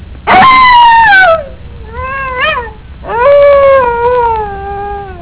WOLF_1.wav